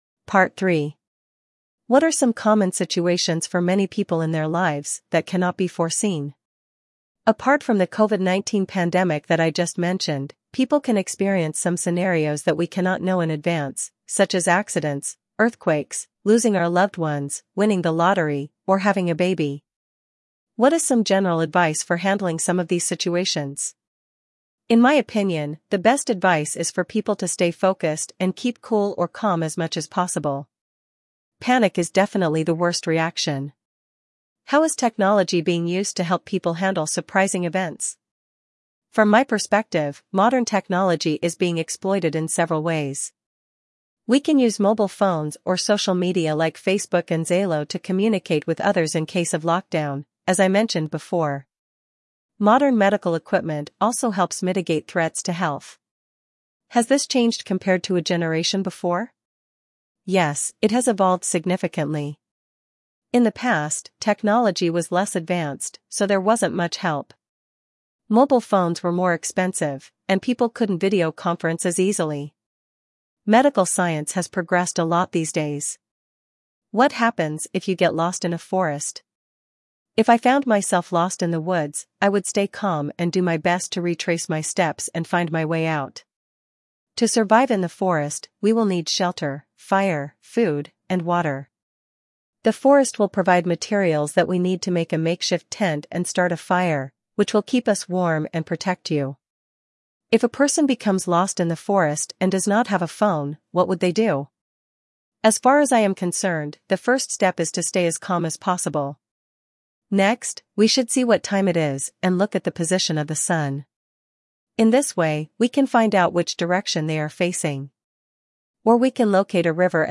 Jenny (English US)